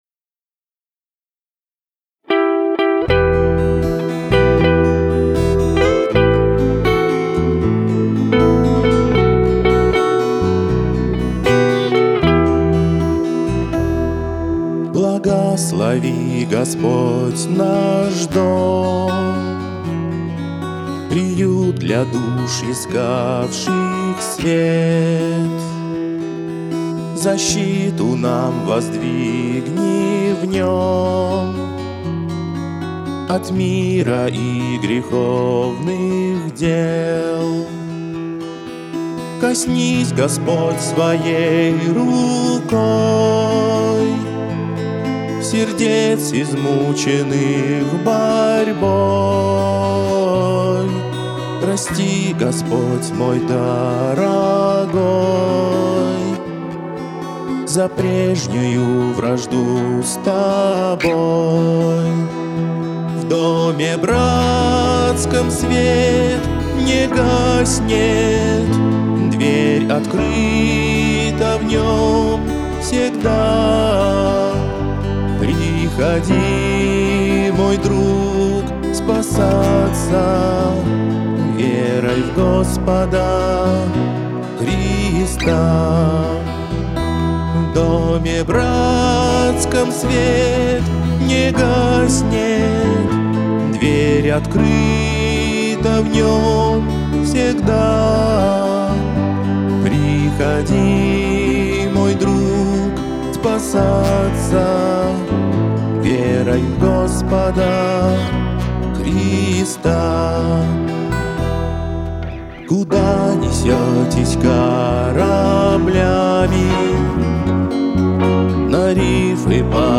Сначала сама песня, потом слова и ноты. И только потом история христианского гимна.
Это уже не песня, это уже гимн Братских домов.